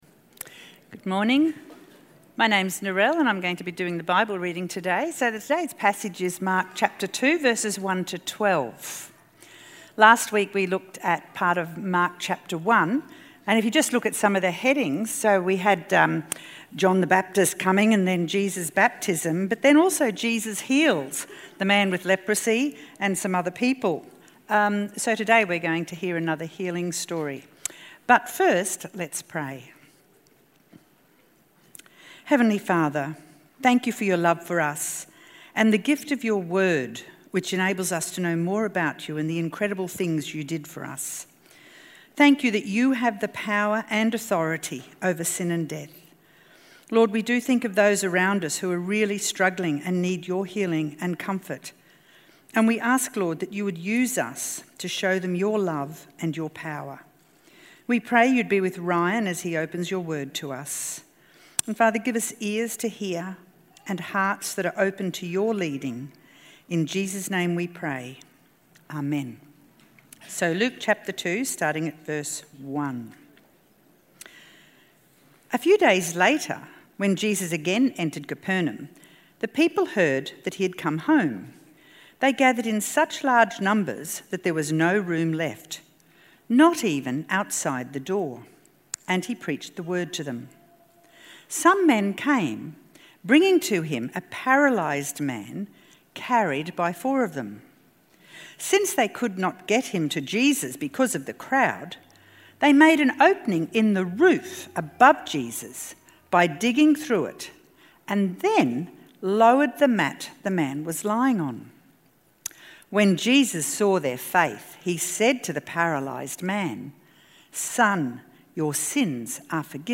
KingOverEvil-ReadingAndTalk.mp3